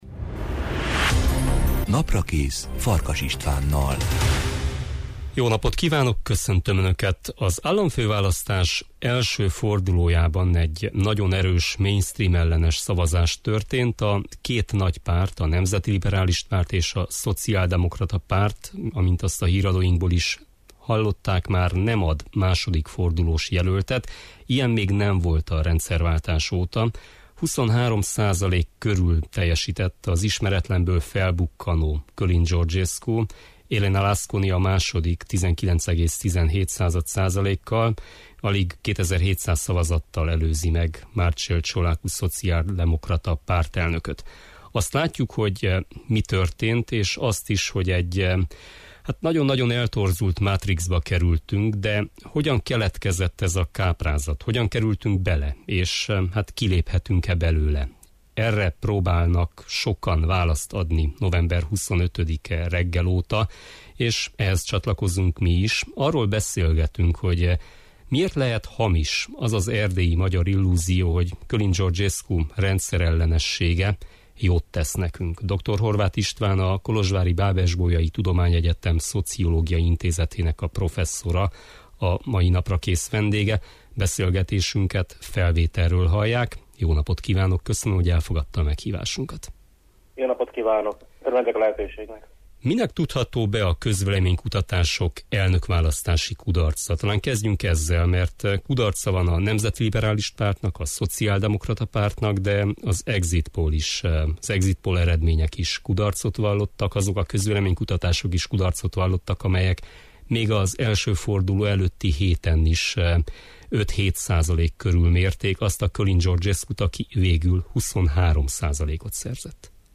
Arról beszélgetünk, hogy miért lehet hamis az az erdélyi magyar illúzió, hogy Călin Georgescu rendszerellenessége jót tesz nekünk.